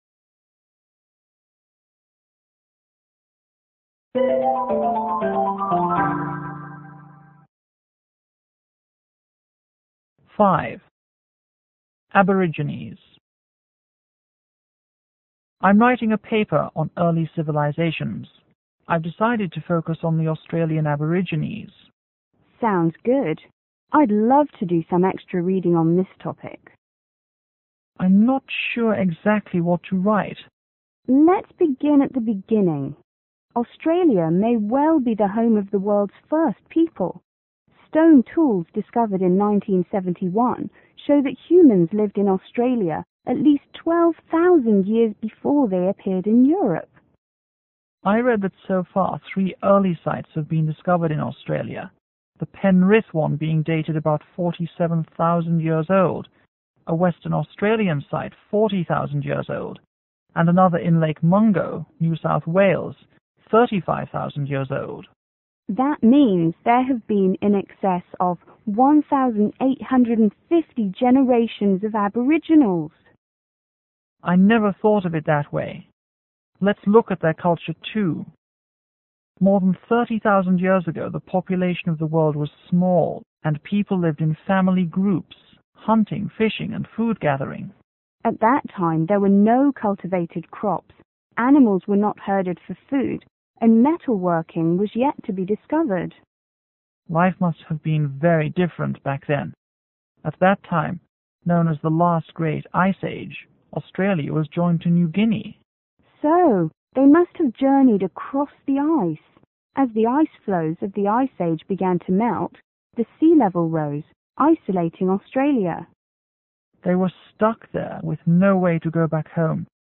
S1:Student 1       S2:Student2